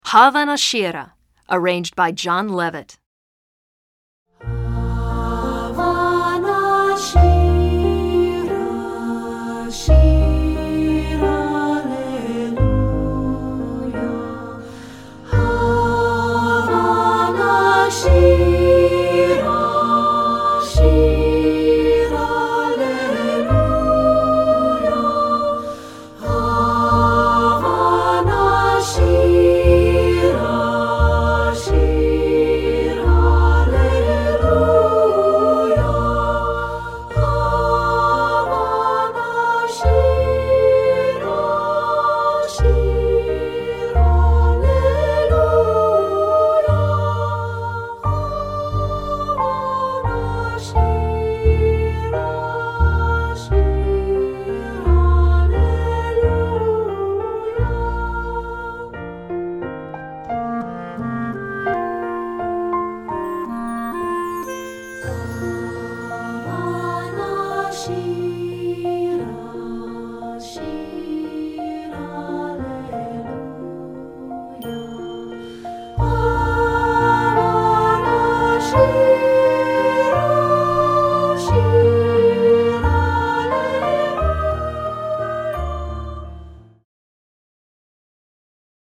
Voicing: 3-Part